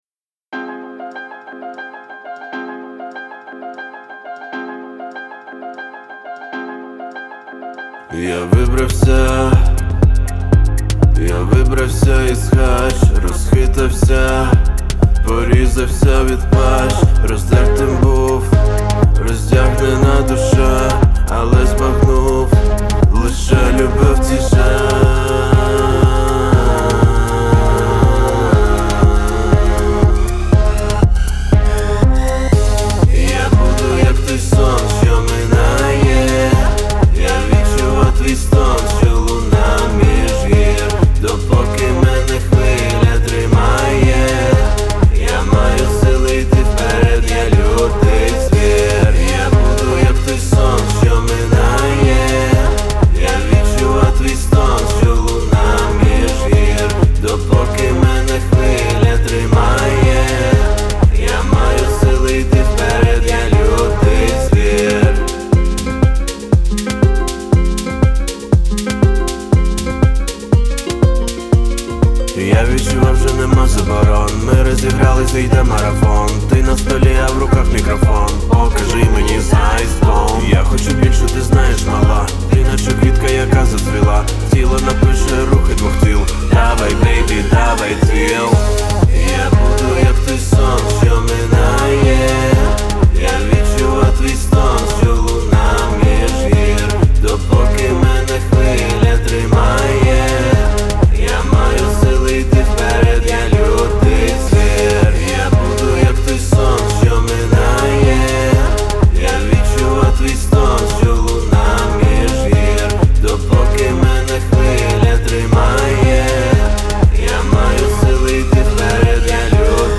• Жанр: Rap, Pop